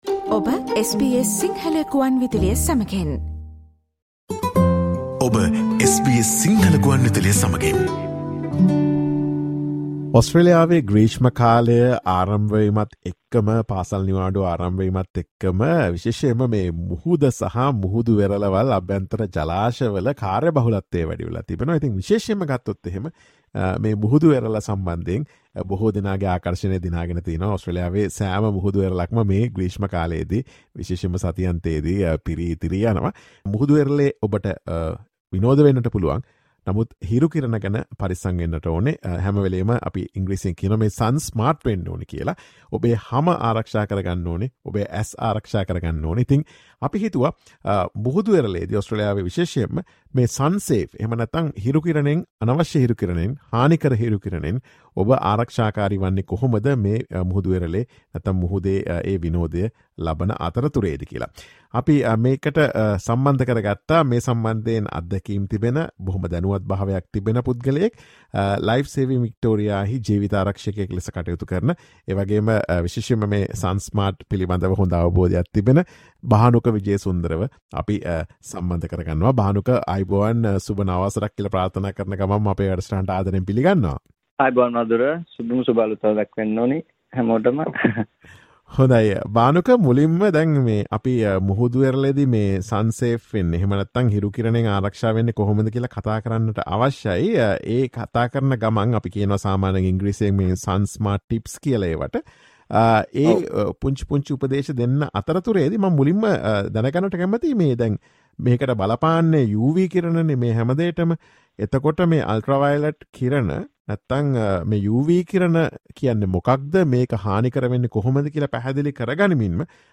Listen to SBS Sinhala Radio's discussion on how to be sun safe at the beach.